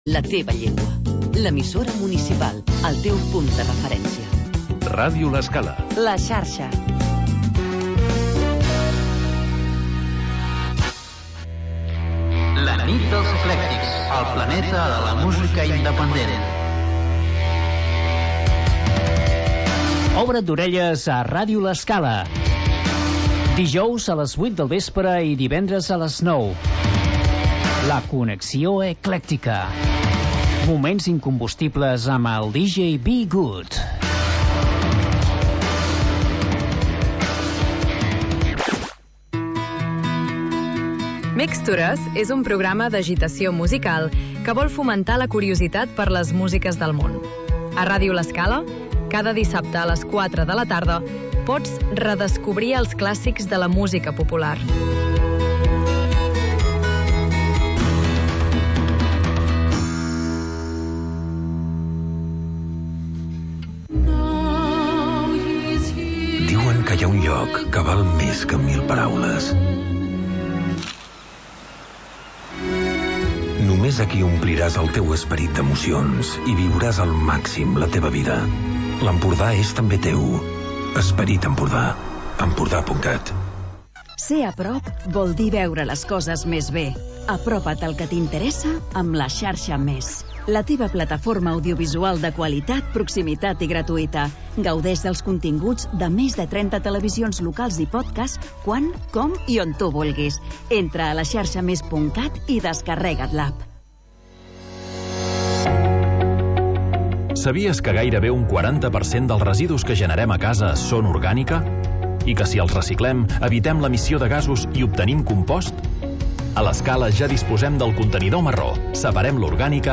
Magazín d'entreteniment per acompanyar el migdia